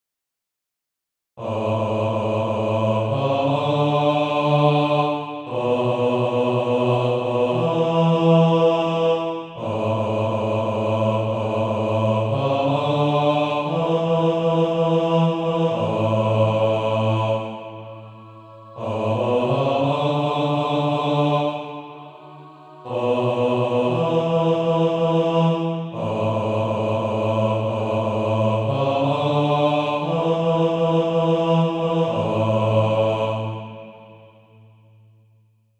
Bass Track.
(SATB) Author
Practice then with the Chord quietly in the background.